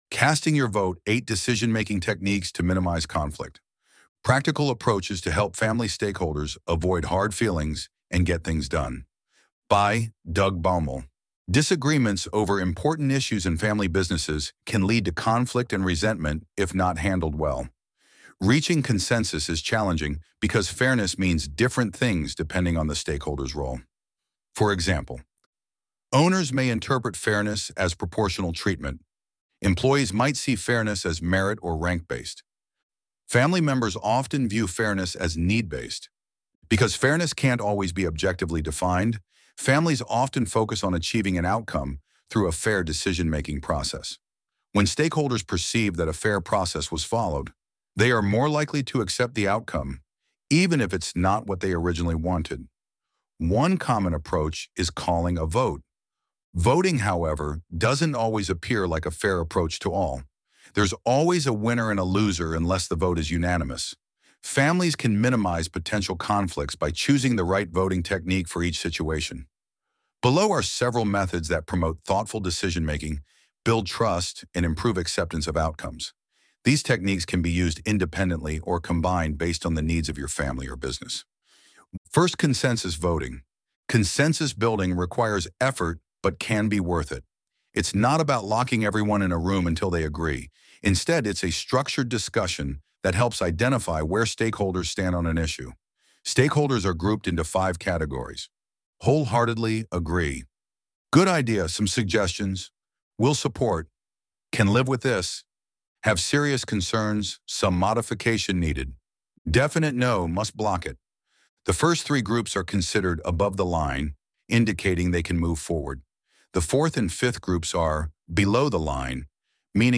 Casting Your Vote: 8 Decision-Making Techniques to Minimize Conflict Practical approaches to help family stakeholders avoid hard feelings and get things done Loading the Elevenlabs Text to Speech AudioNative Player...